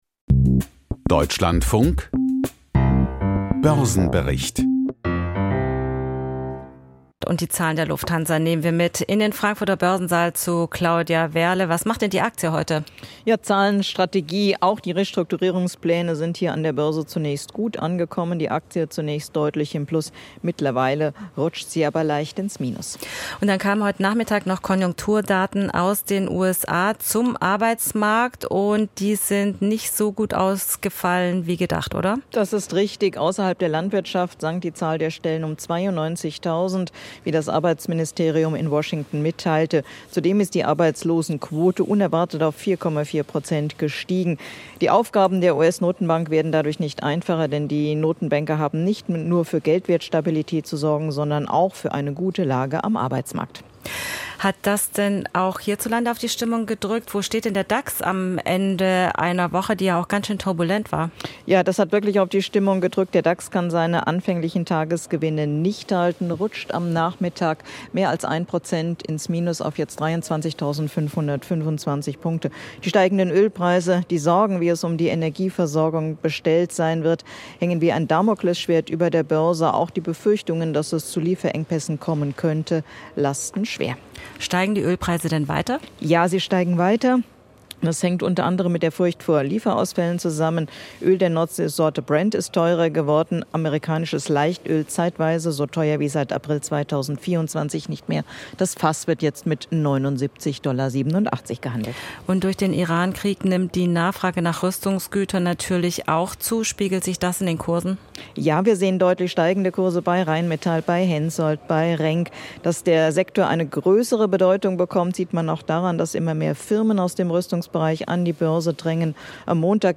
Börsenbericht aus Frankfurt a.M.